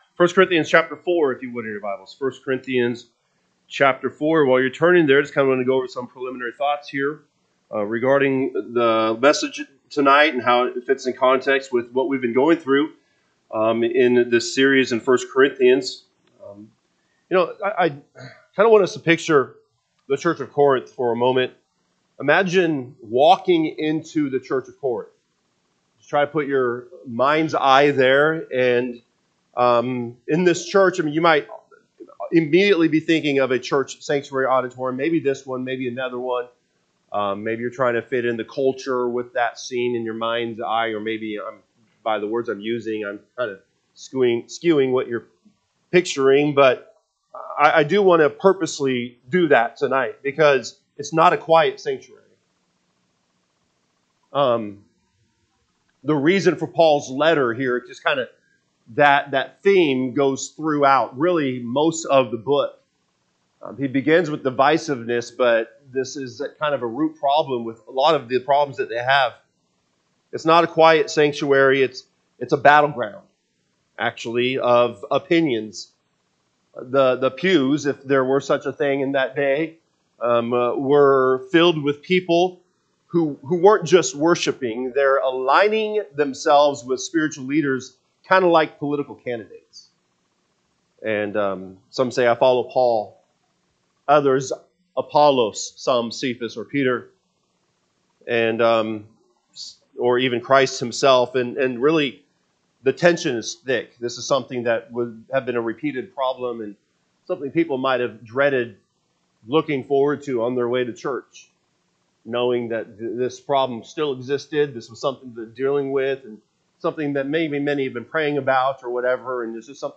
September 14, 2025 pm Service 1 Corinthians 4:1-5 (KJB) 4 Let a man so account of us, as of the ministers of Christ, and stewards of the mysteries of God. 2 Moreover it is required in ste…